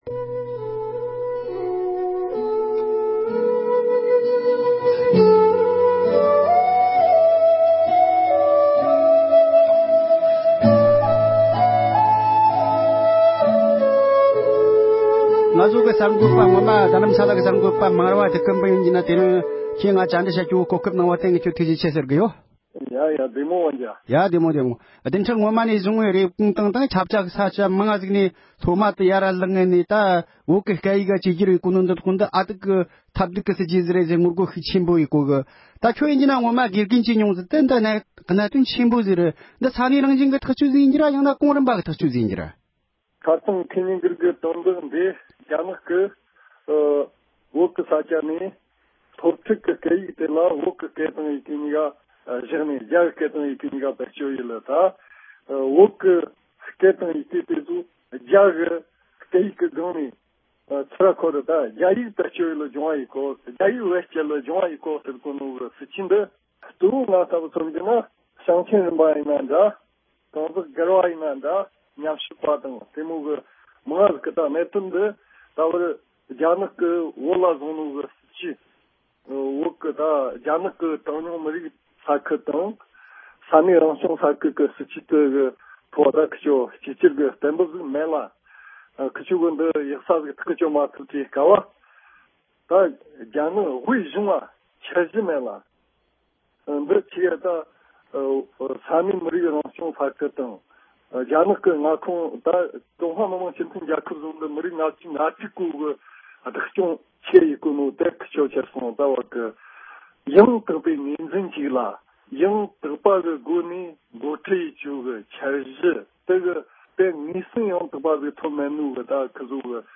བོད་ནང་གི་སློབ་གྲྭ་ཁག་གི་ནང་སློབ་ཚན་བསྒྱུར་བཅོས་བྱེད་ཕྱོགས་ཐད་གླེང་མོལ།